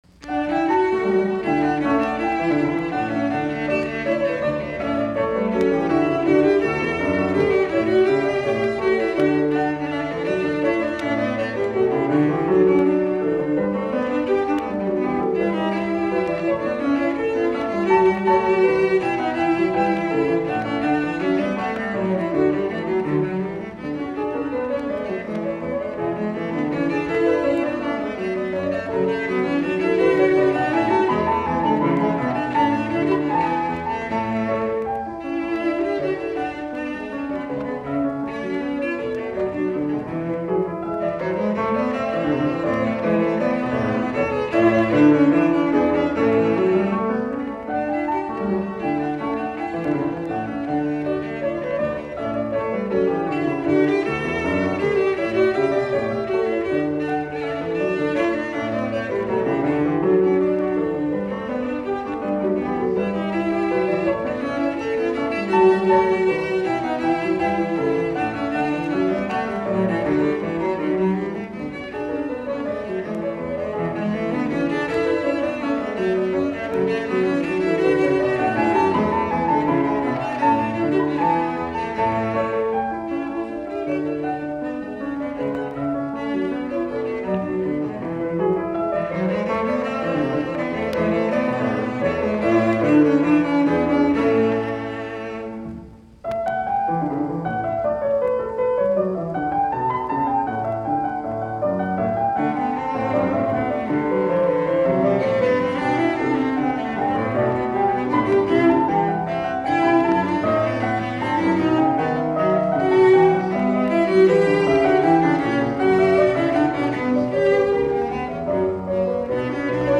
sello, piano